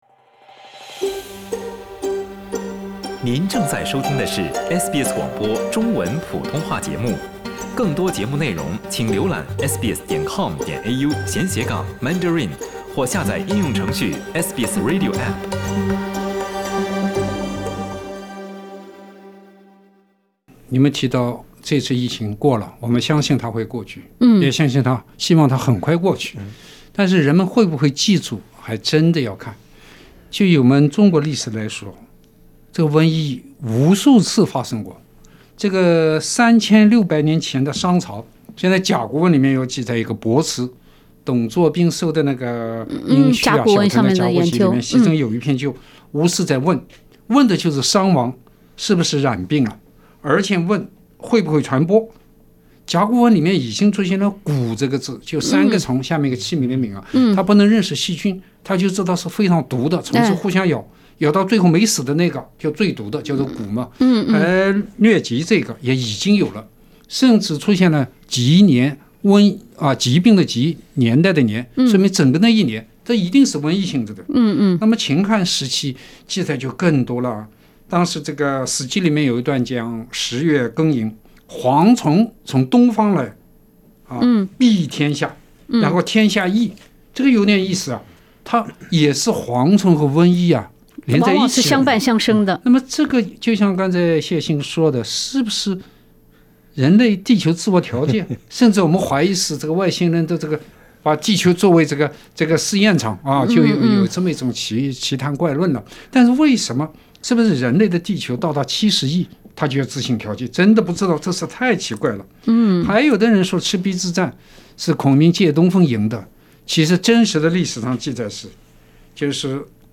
SBS电台《文化苦丁茶》每周五早上澳洲东部时间早上8:15播出，每周日早上8:15重播。